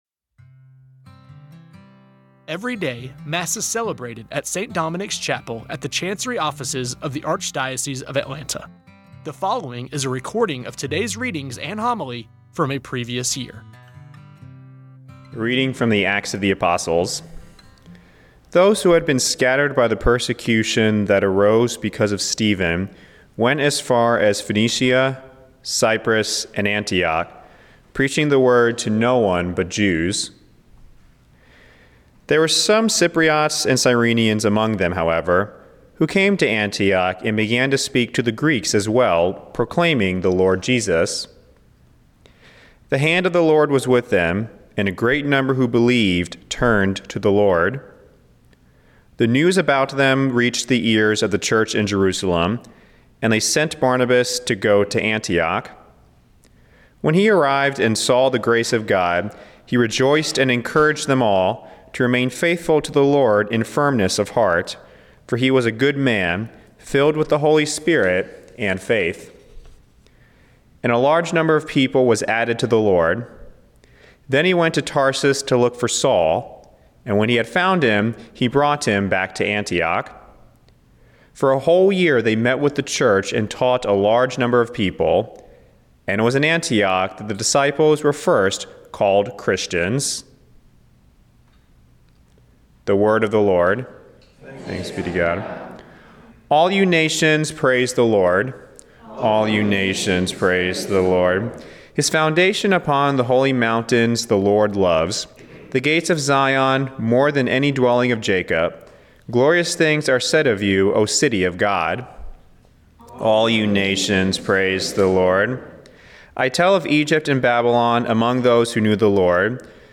Every day, Mass is celebrated at St. Dominic’s Chapel at the Chancery Offices of the Archdiocese of Atlanta. The following is a recording of today’s readings and homily from a previous year. You may recognize voices proclaiming the readings and homilies as employees, former employees, or friends of the Archdiocese.
Today’s homily is given by Bishop Ned Shlesinger from May 2, 2023.